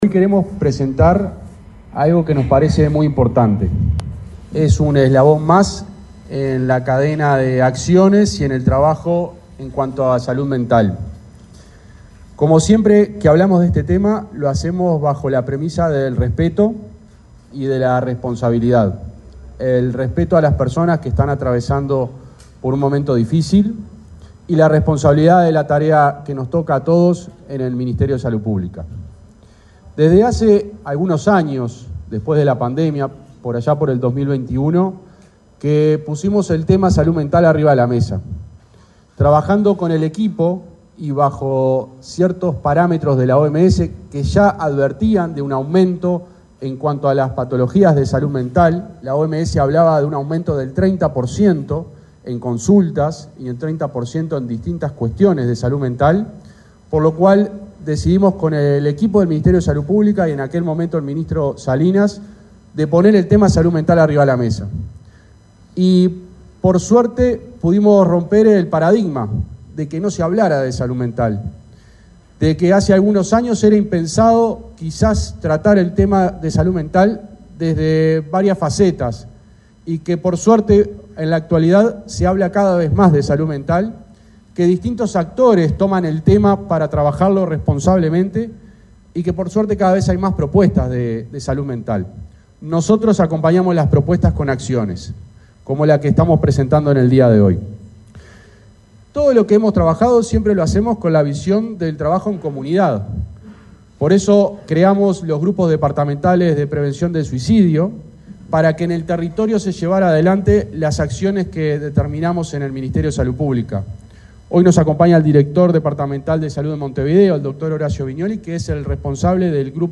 Palabras del subsecretario de Salud Pública, José Luis Satdjian
Palabras del subsecretario de Salud Pública, José Luis Satdjian 27/08/2024 Compartir Facebook X Copiar enlace WhatsApp LinkedIn El subsecretario del Ministerio de Salud Pública, José Luis Satdjian, participó, este martes 27 en la sede de esa cartera, en el lanzamiento de una guía de recomendaciones para el proceso asistencial de personas con ansiedad y depresión.